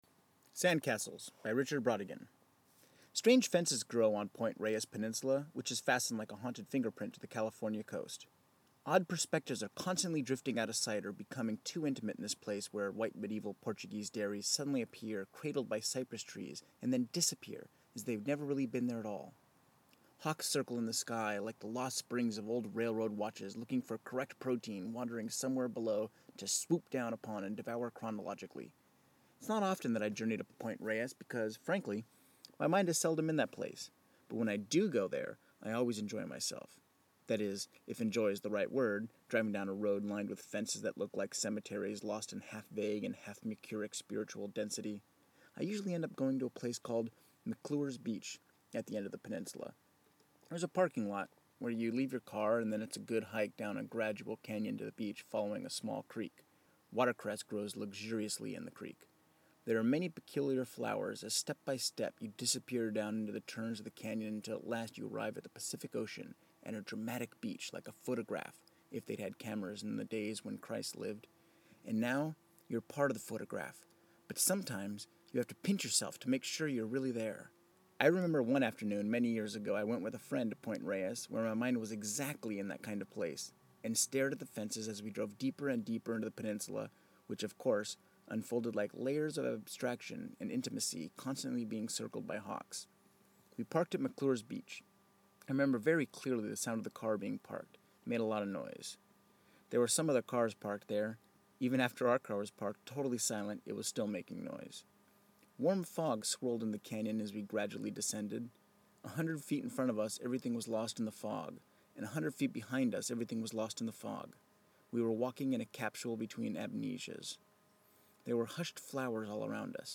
DIY Audio Book